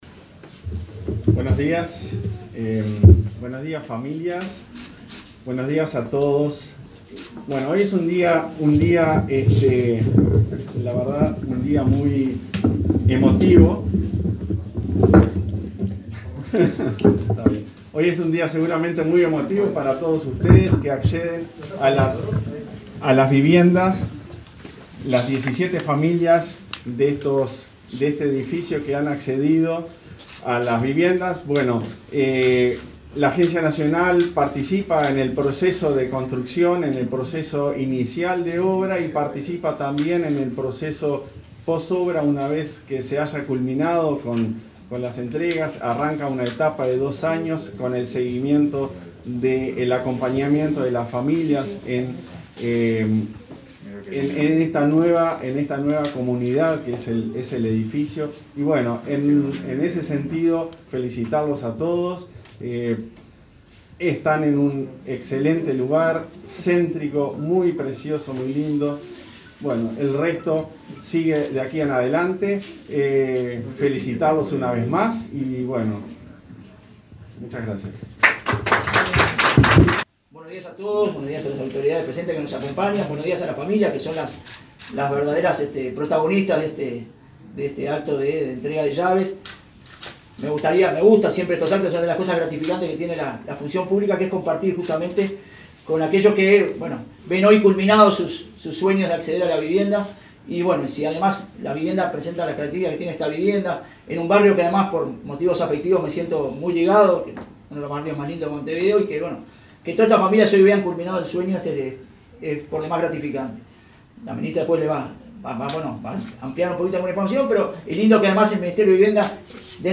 Conferencia de prensa por entrega de viviendas en Montevideo
Participaron del acto la ministra de Vivienda y Ordenamiento Territorial, Irene Moreira; el director nacional de Vivienda, Jorge Ceretta, y el presidente de la Agencia Nacional de Vivienda, Klaus Mill.